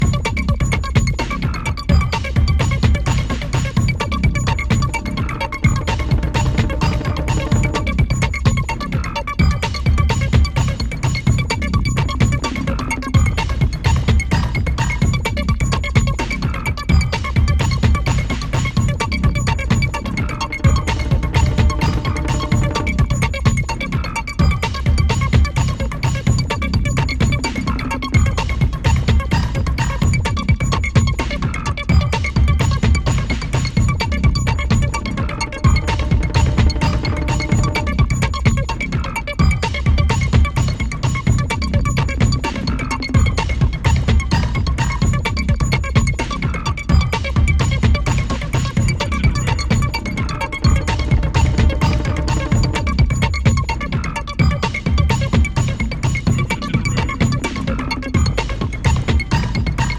Electronix Techno